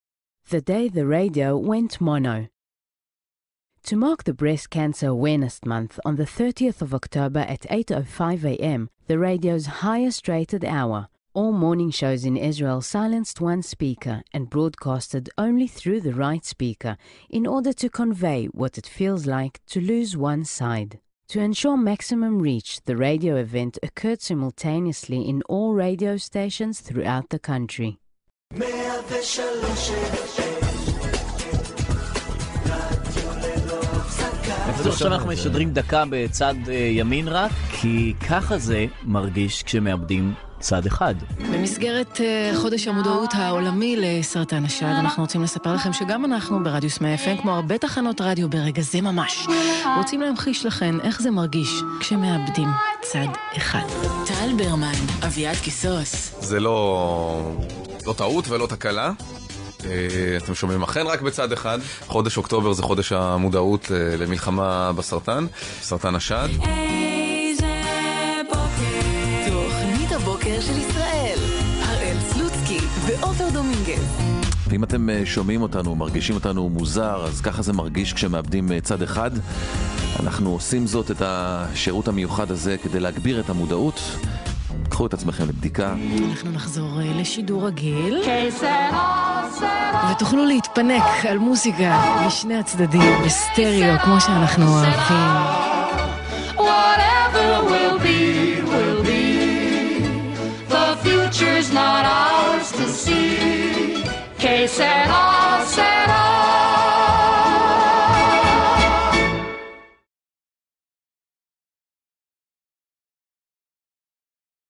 All morning shows broadcasted out of the right speaker only.
And to achieve maximum reach of that message, every radio station in the entire country silenced their left speaker simultaneously at 8:05am:
the-day-the-radio-went-mono.mp3